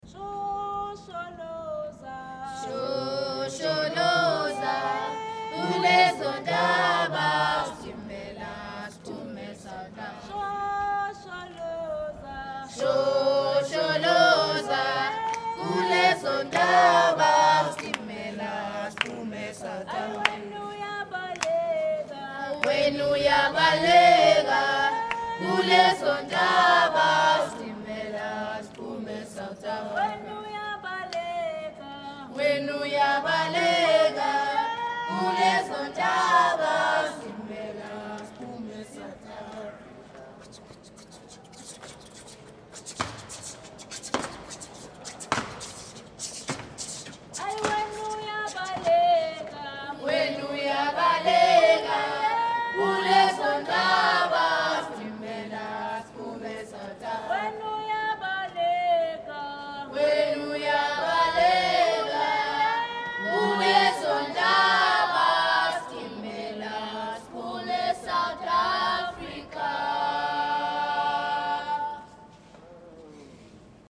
Als ich vorgestern auf dem Campus der Universität war, um die Podiumsdiskussion über "Tourismus und Klimagerechtigkeit" zu besuchen, wurde ich von einer Gruppe Jugendlicher in den Bann gezogen: Die Jungs und Mädchen aus Malawi haben so grandios gesungen, das war die beste Werbung für ihr Projekt!